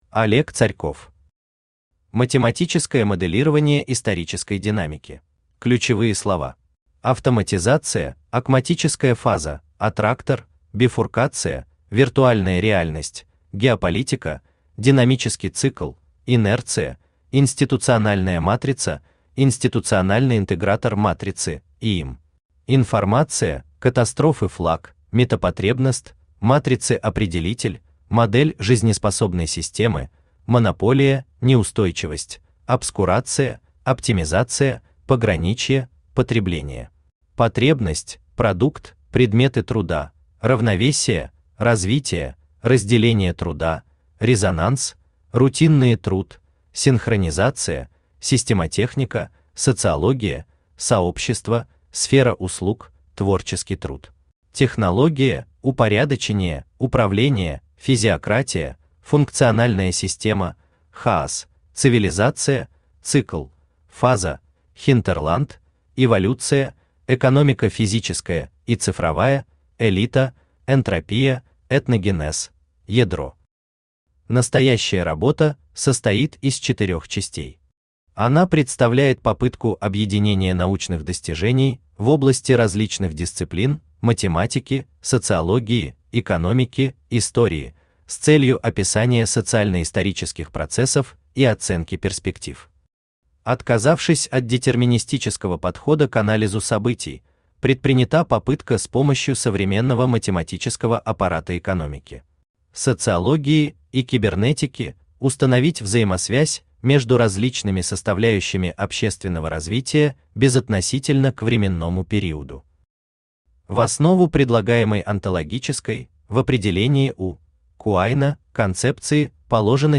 Aудиокнига Математическое моделирование исторической динамики Автор Олег Евгеньевич Царьков Читает аудиокнигу Авточтец ЛитРес.